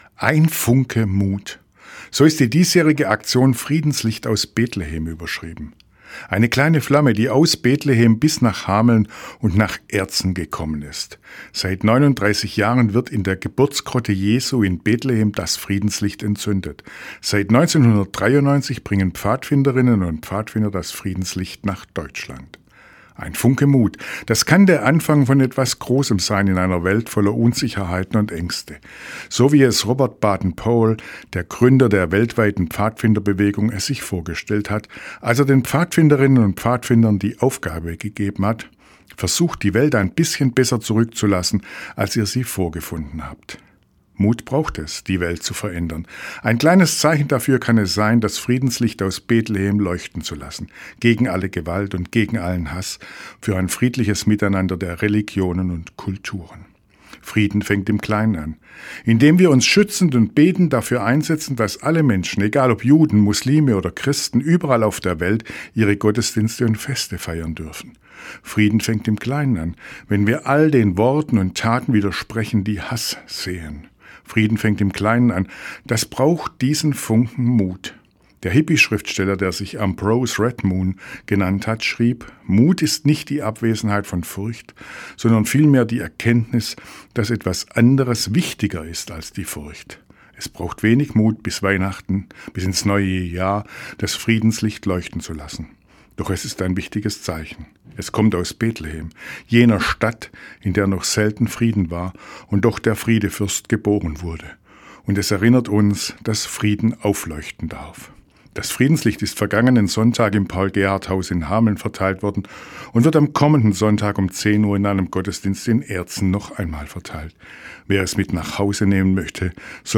Radioandacht vom 16. Dezember